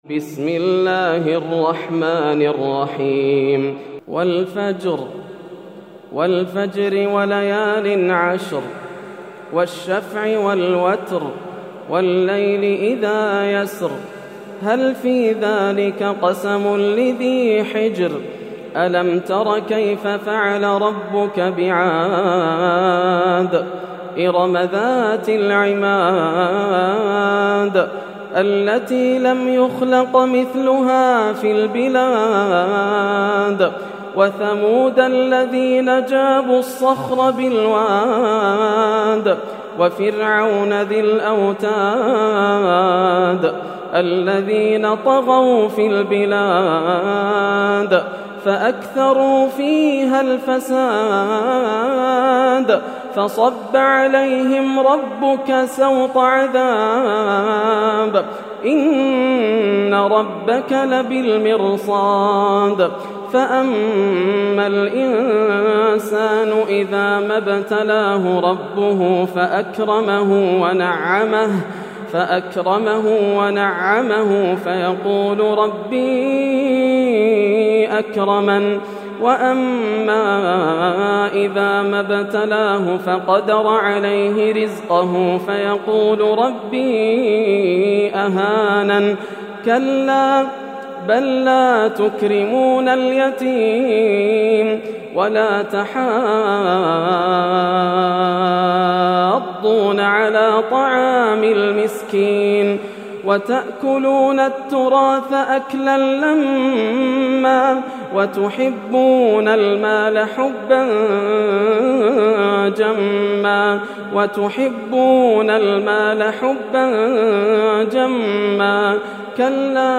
سورة الفجر > السور المكتملة > رمضان 1431هـ > التراويح - تلاوات ياسر الدوسري